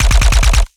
Added more sound effects.
GUNAuto_RPU1 C Burst_01_SFRMS_SCIWPNS.wav